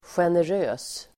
Uttal: [sjener'ö:s]